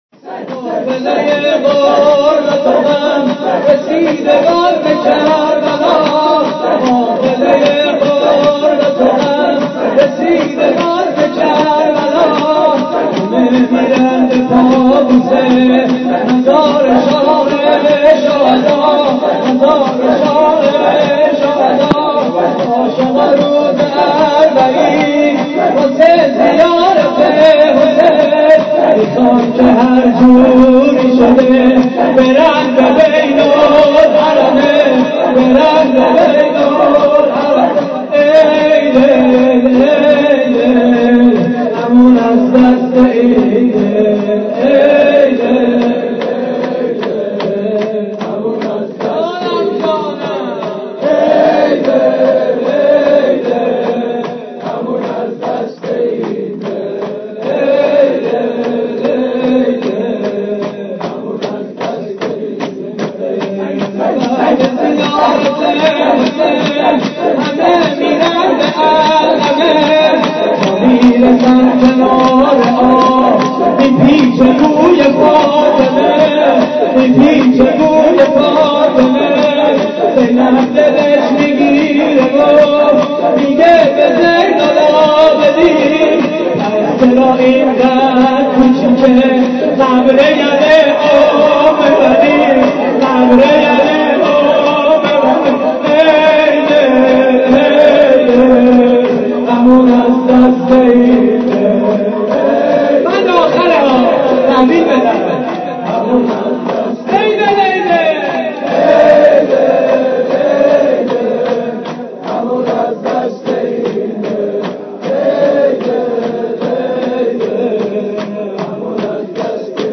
شور: قافله‌ی غربت و غم
مراسم عزاداری اربعین حسینی